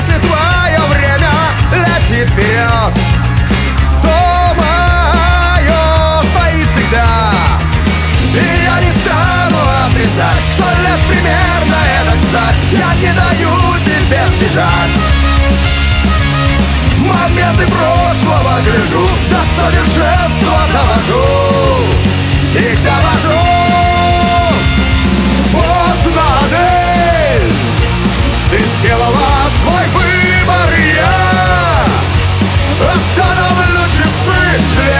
Жанр: Рок / Русские
# Rock